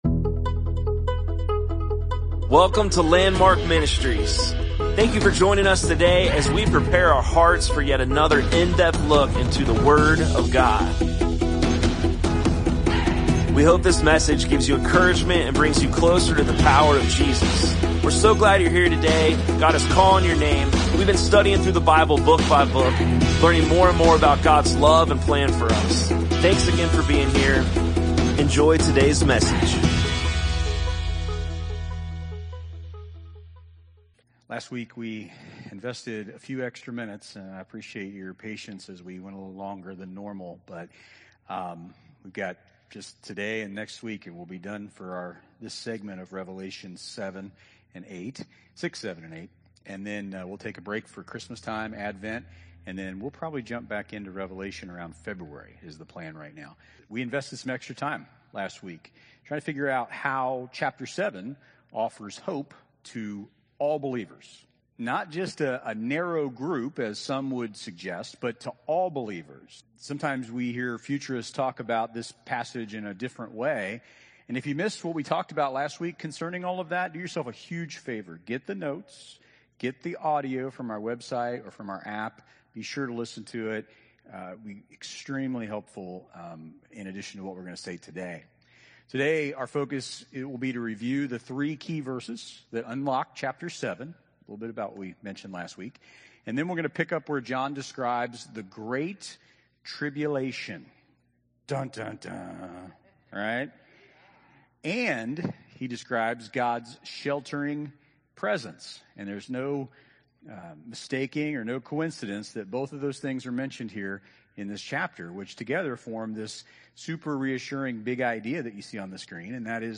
Sermons | Landmark Church of Clermont County, Ohio